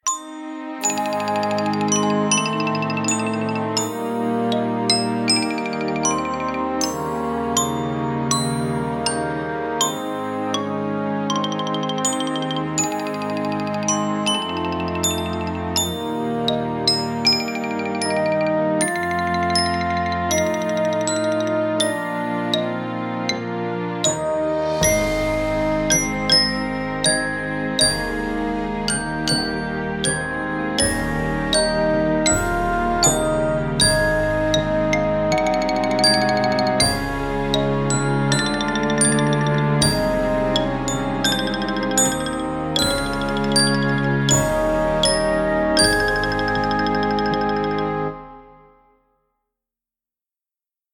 Voicing: Mallet